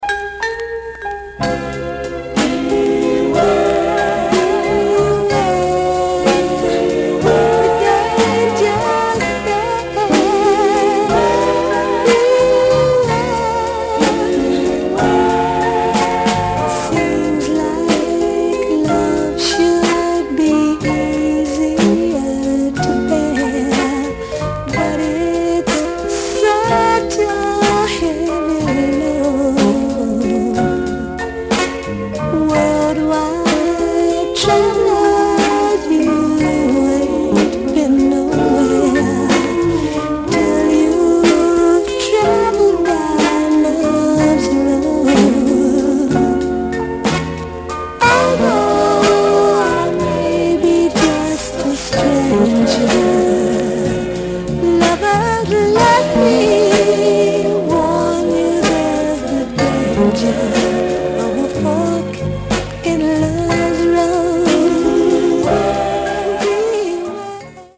An important record in Soul Music history